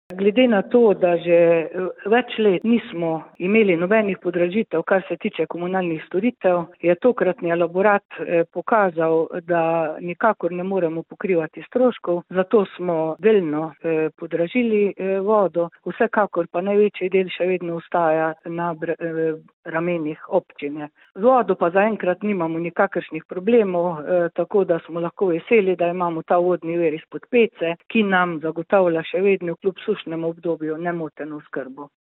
Občinski svet Občine Črna na Koroškem je na zadnji seji po več letih podražil oskrbo s pitno vodo. Županja Romana Lesjak:
izjava zupanja Lesjak 1  ZA SPLET.mp3